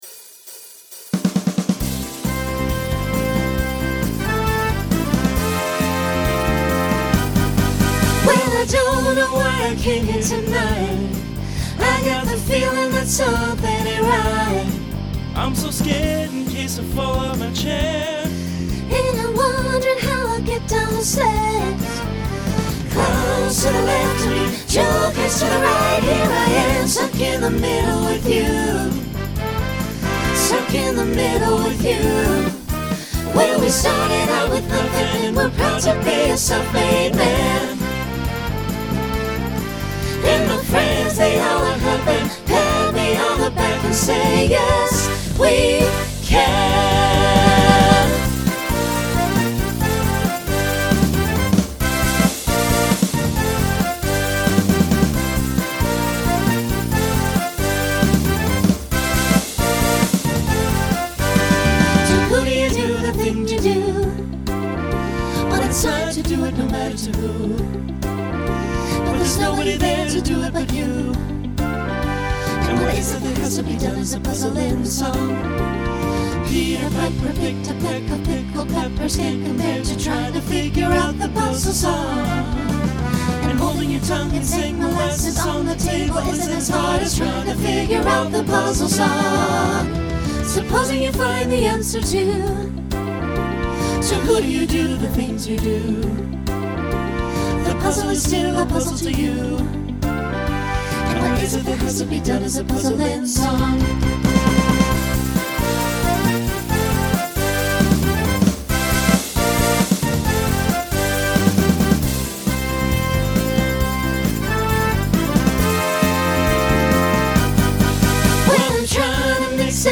Genre Rock , Swing/Jazz
Story/Theme Voicing SATB